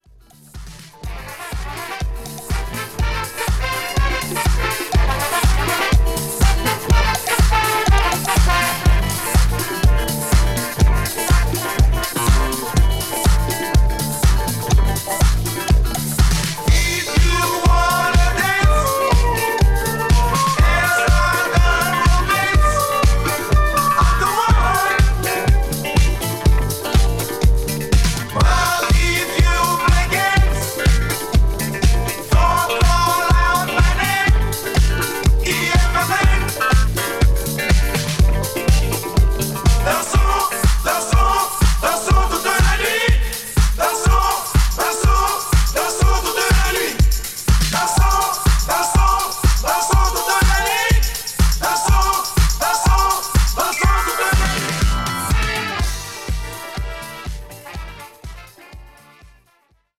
SOUL / FUNK / RARE GROOVE / DISCO